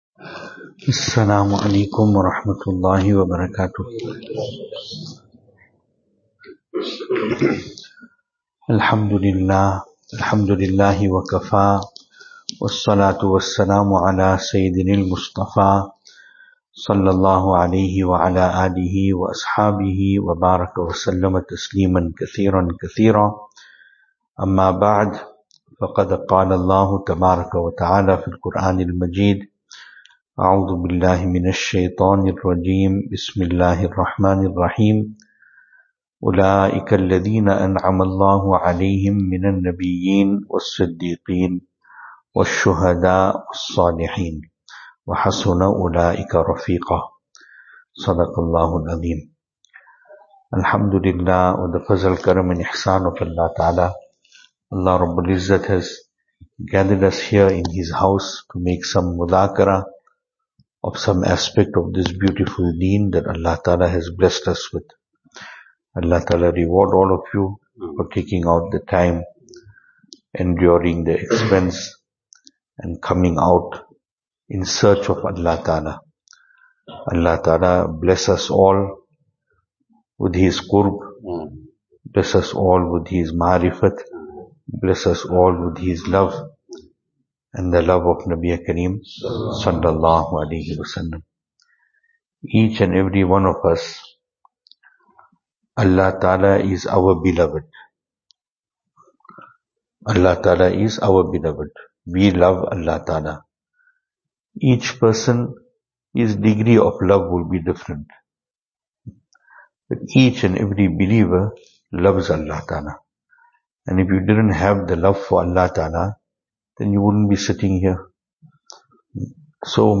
2025-08-16 Overnight Program – After Magrib Venue: Albert Falls , Madressa Isha'atul Haq Series: Overnight Service Type: Overnight « Seeking protection from crookedness coming to our hearts Overnight Program – 9pm.